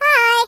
project_files/Data/Sounds/voices/Default/Hello.ogg
Hello.ogg